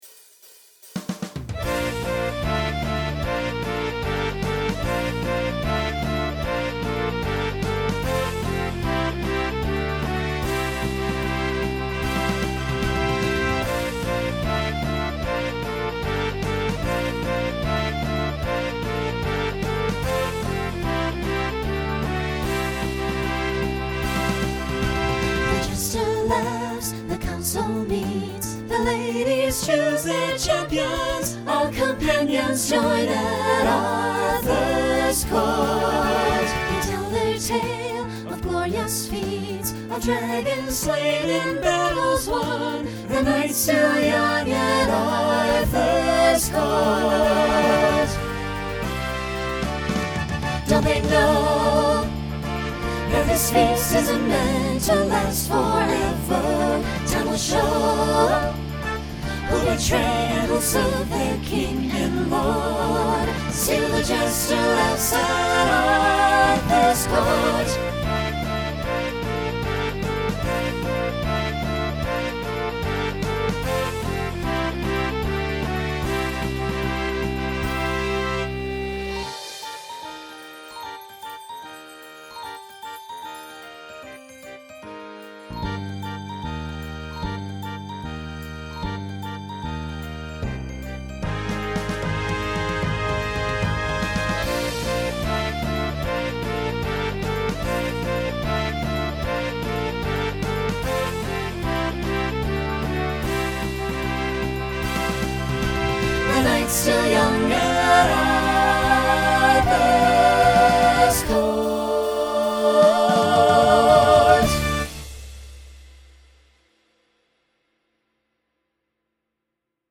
Decade 1980s Genre Rock Instrumental combo
Story/Theme Voicing SATB